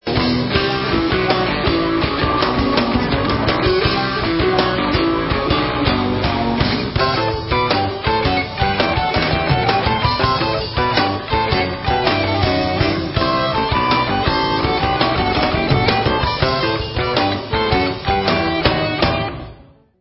Recorded live november 1994